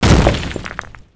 bustconcrete2.wav